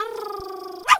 dog_2_small_bark_04.wav